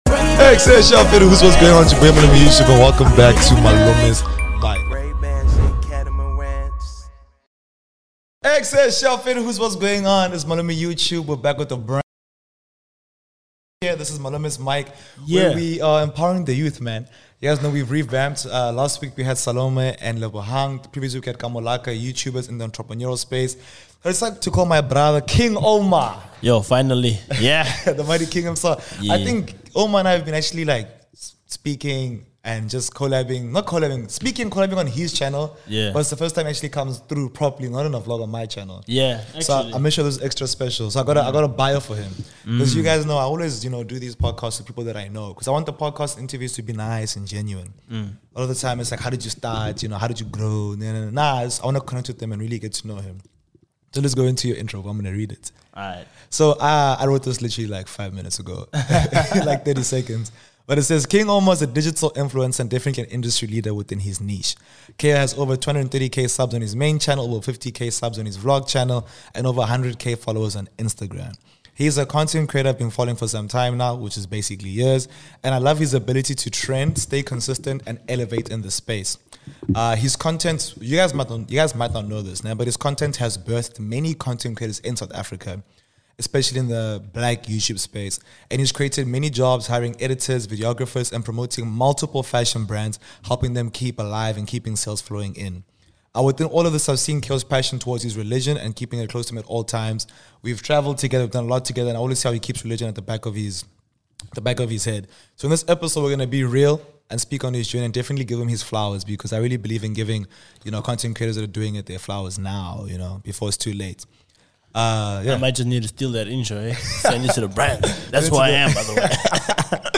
In this exciting interview